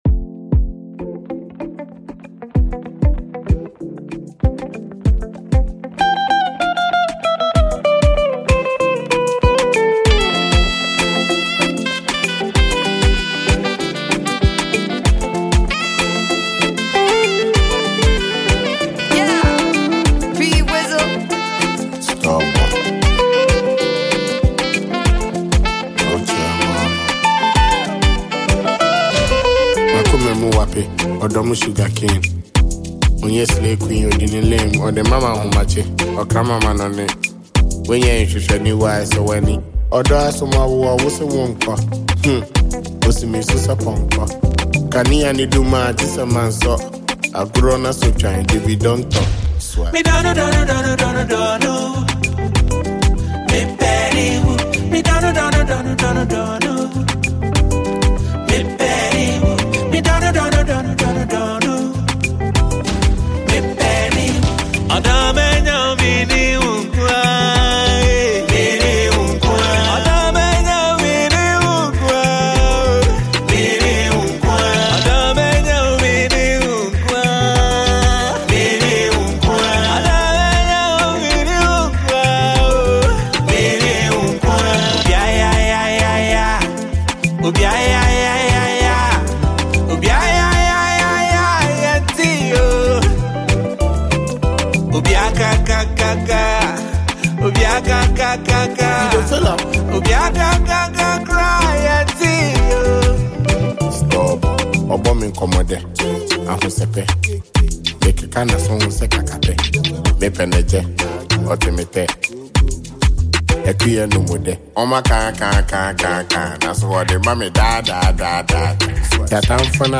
a Ghanaian super talented rapper